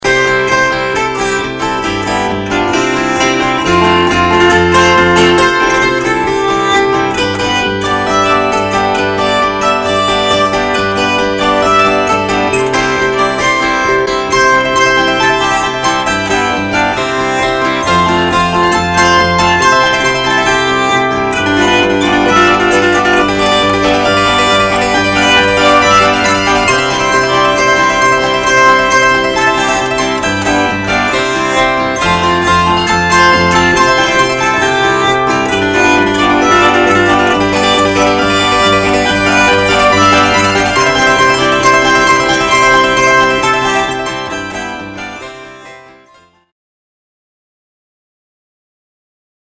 ukelin and chord-zither
First up are a couple of old favorites, played on a Bosstone ukelin and a Columbia Special No. 2 1/4 chord-zither.